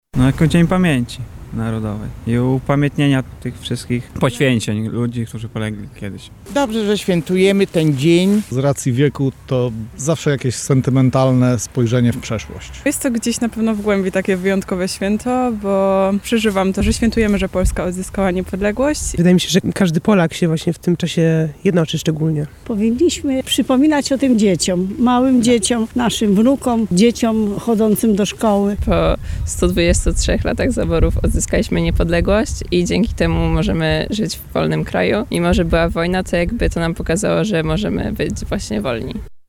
W tym wyjątkowym dniu zapytaliśmy mieszkańców Lublina, czym dla nich jest to święto:
SONDA